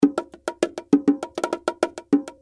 100 bpm bongo loops (9 variations)
Real bongo loops at 100 bpm. 9 loops of bongos in high quality.
Get the authentic sound of real bongo drums recorded with top-tier studio gear and professional precision. This exclusive pack includes 9 inspiring bongo loops and fills, all recorded at 100 BPM.
🎙 Recorded with premium microphones: